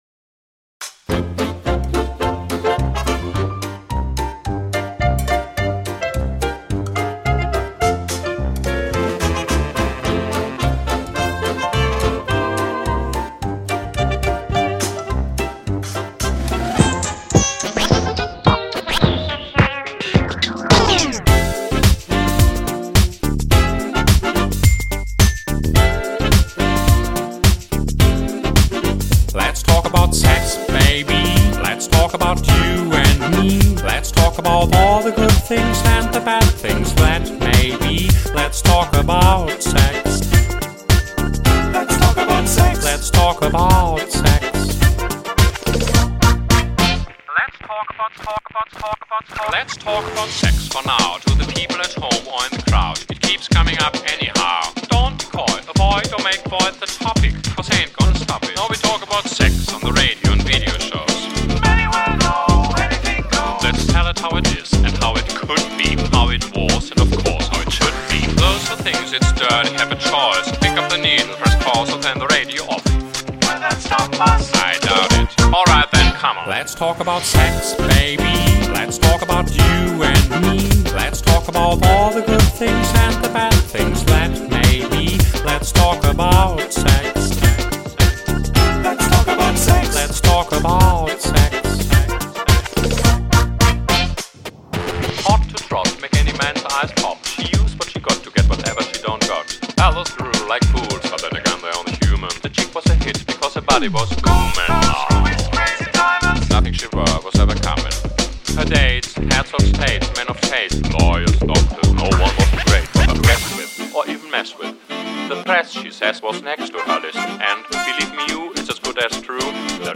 German dance music of the 1920’s and 30’s
genius clash of song and style